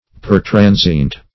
Search Result for " pertransient" : The Collaborative International Dictionary of English v.0.48: Pertransient \Per*tran"sient\, a. [L. pertransiens, p. pr. of pertransire.] Passing through or over.